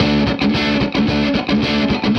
AM_HeroGuitar_110-D02.wav